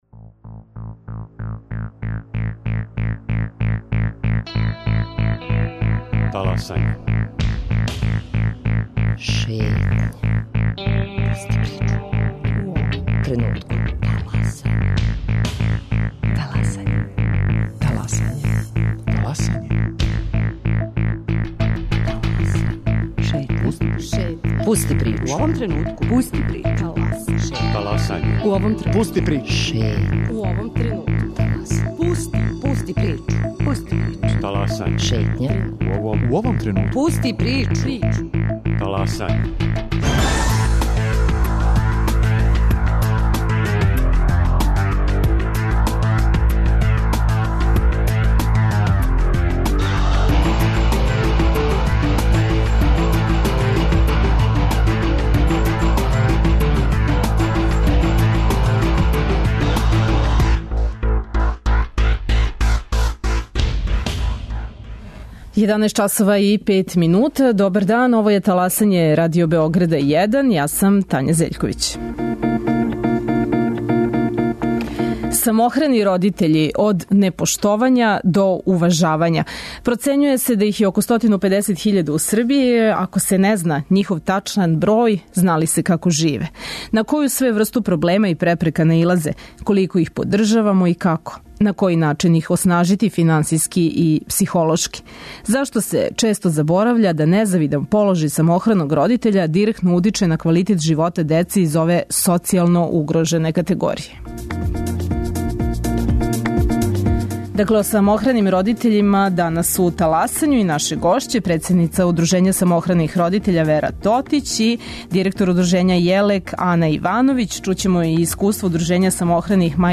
Чућемо и искуства удружења самохраних мајки из Ниша.